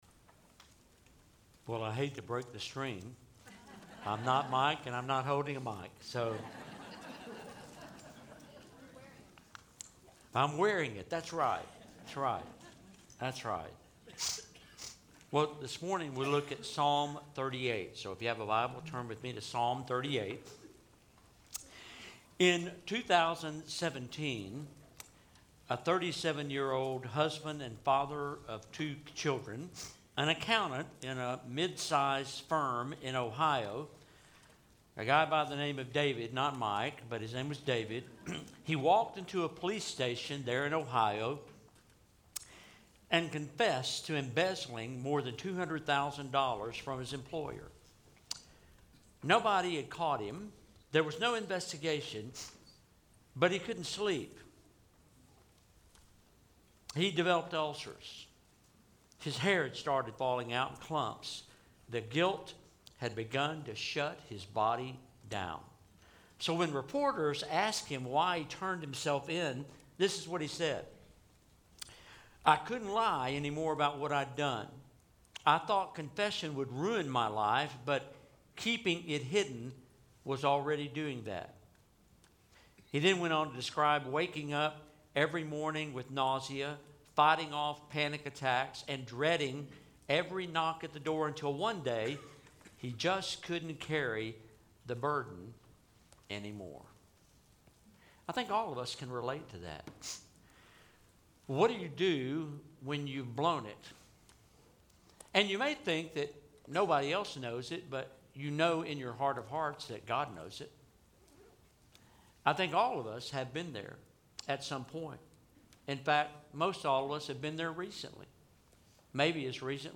Lesson